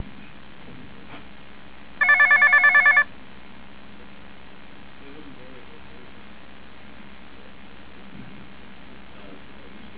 A single ring is heard every 60 seconds as a reminder.
singlering.au